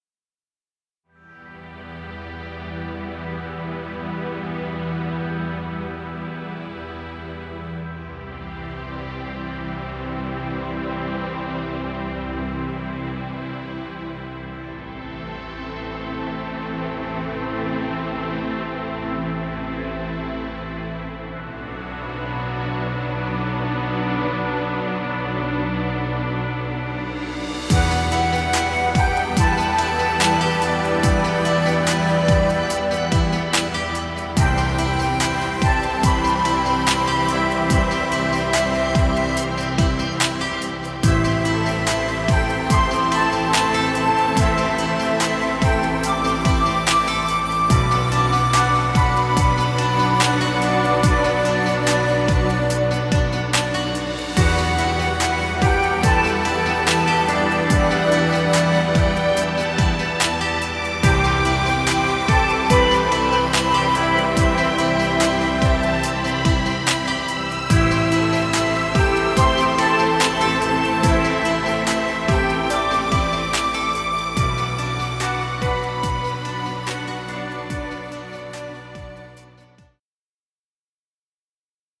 Enigmatic chillout music.